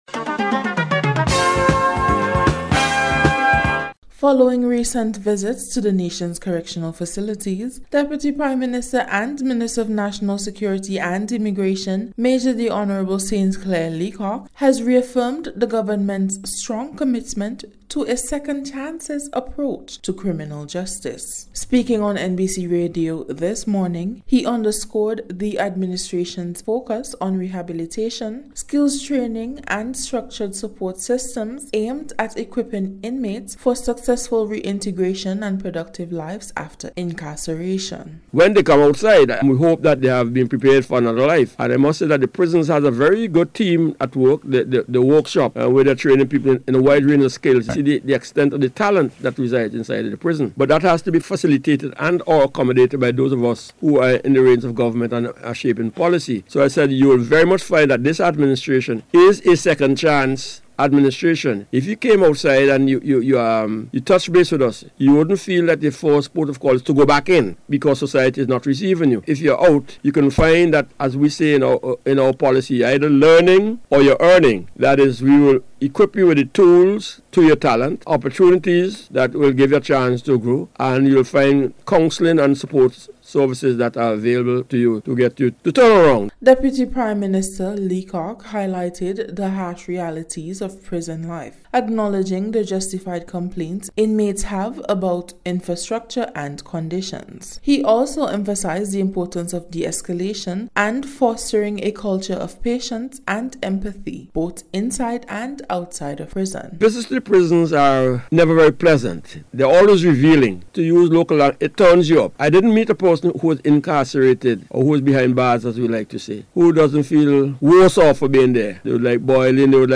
special report